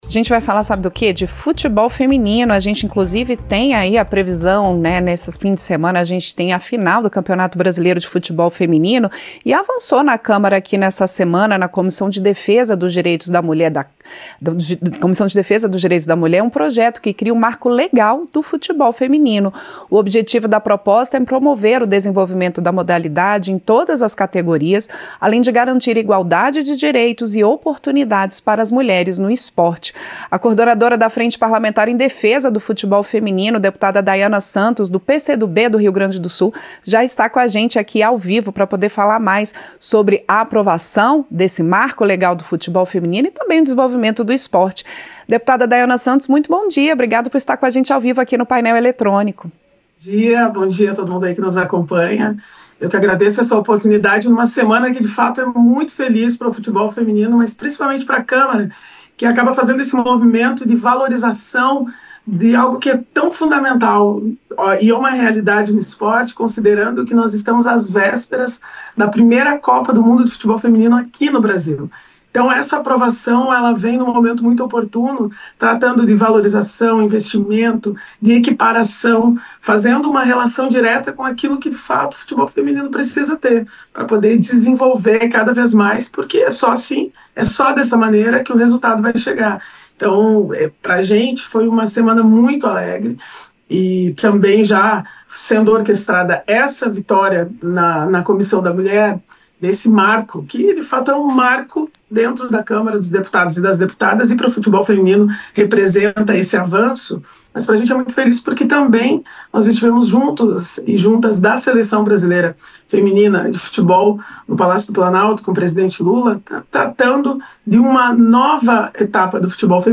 Entrevista: Dep. Daiana Santos (PCdoB-RS)